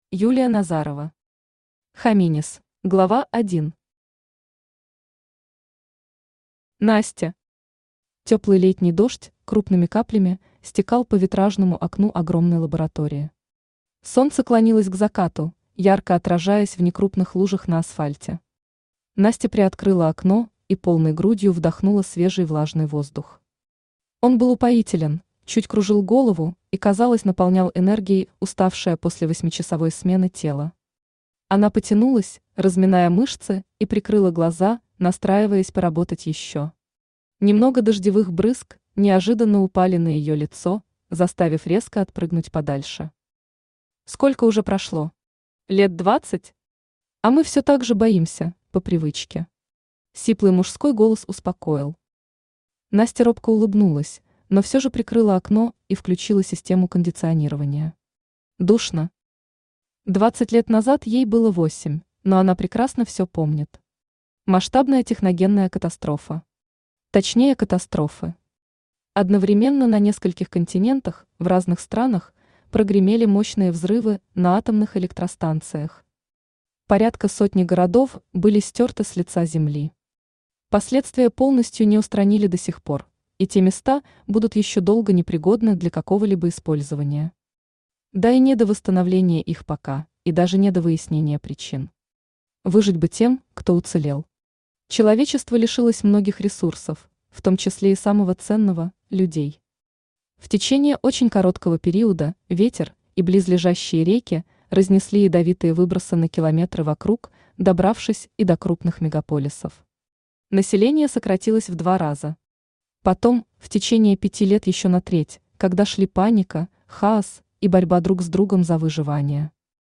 Aудиокнига Хоминис Автор Юлия Назарова Читает аудиокнигу Авточтец ЛитРес.